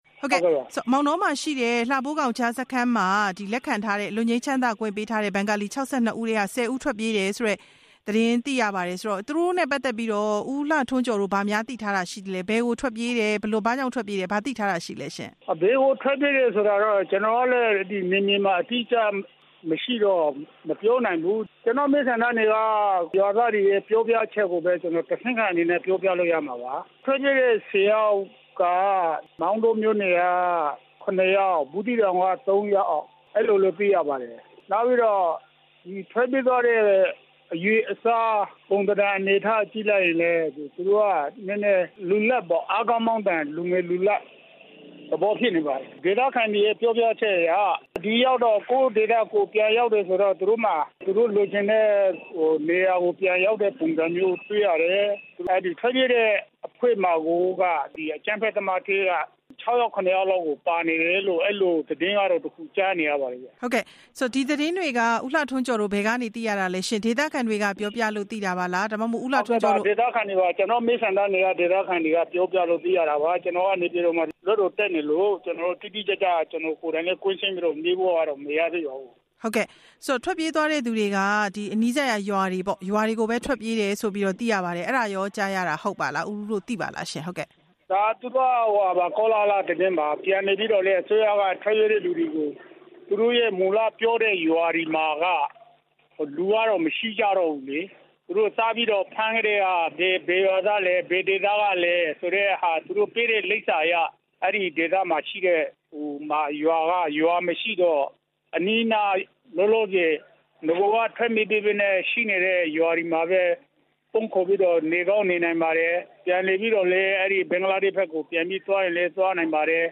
လှဖိုးခေါင်စခန်းက ပြန်ရောက်လာတဲ့ ဒုက္ခသည် ၆၂ ဦးအကြောင်း မေးမြန်းချက်